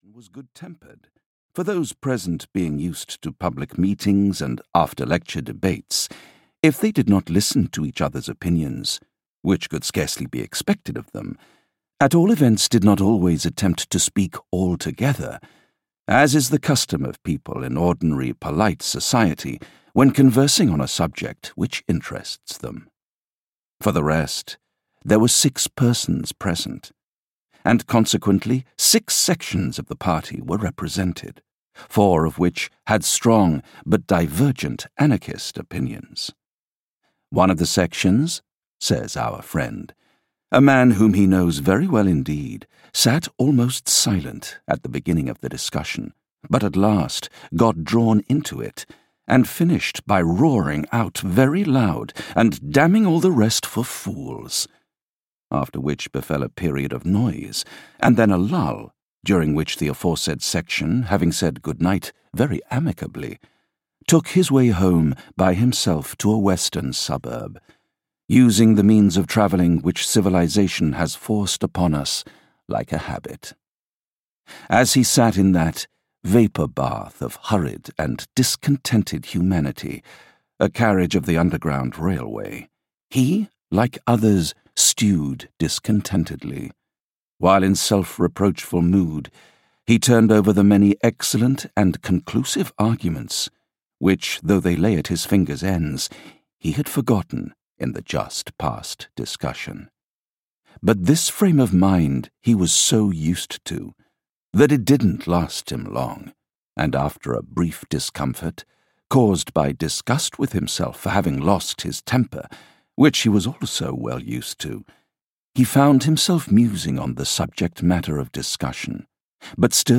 News from Nowhere (EN) audiokniha
Ukázka z knihy